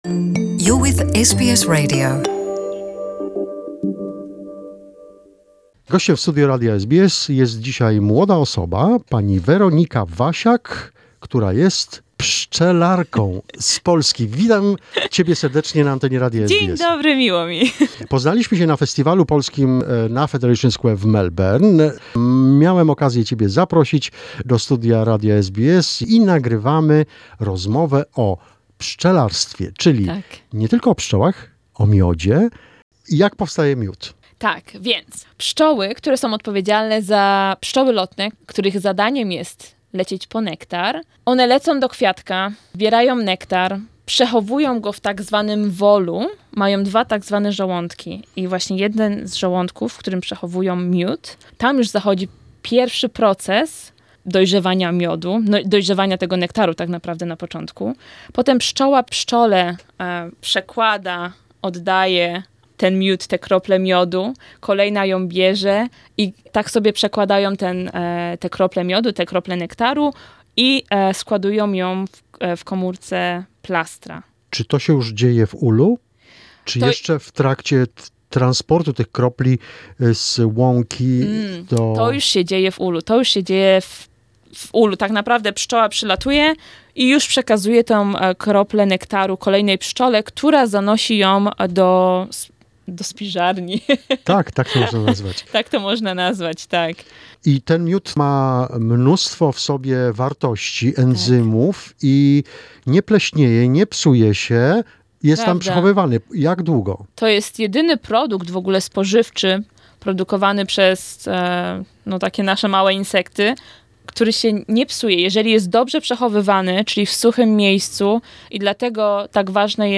How the bee’s colonies function and what is the beehive’s hierarchy? Also on the disease that decimates the world beehives but not Australian’s ones. 2nd part of the interview with Polish beekeeper who visits Australia and New Zealand’ apiarists.